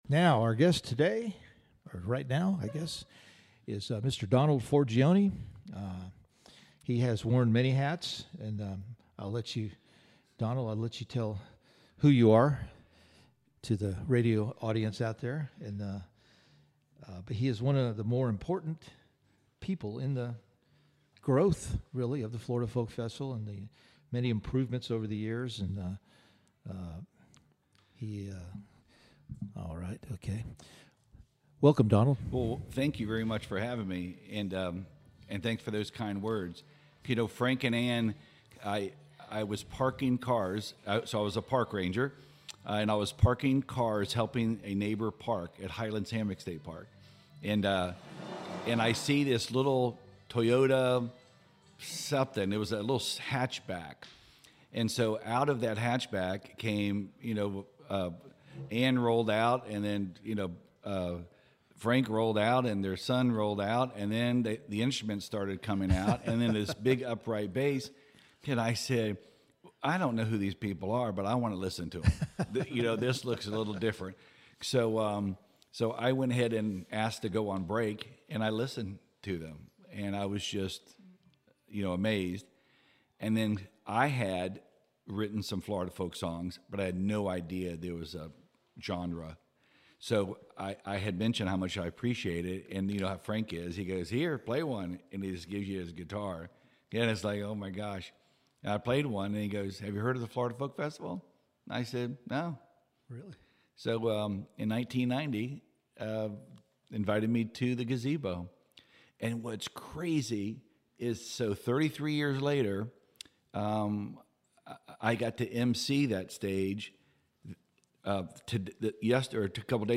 Tailgate Talks" at Florida Folk Festival